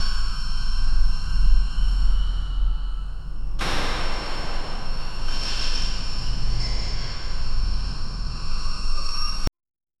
Realistic, industrial, suitable for bunker or underground entrance. 0:10 Faint electrical buzz from the lab side; soft wind whistling through bunker cracks; subtle clinking of glass vials in the lab; distant dripping water echoing in the bunker; layered reverb blending both spaces. 0:10 dingy background noise in abandoned soviet cold war bunker that can seamlessly loop if played on a loop 0:10
faint-electrical-buzz-fro-fhx7djrd.wav